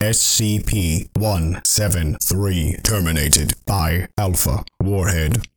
SCp death by warhead